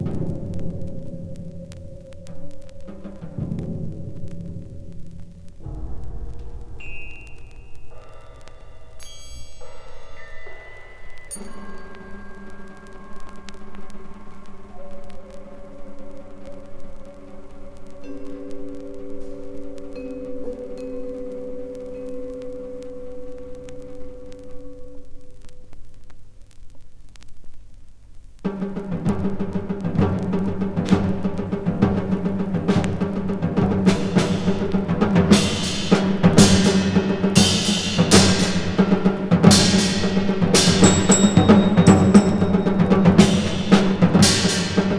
Classical, Contemporary　France　12inchレコード　33rpm　Stereo